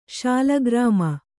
♪ śalagRāma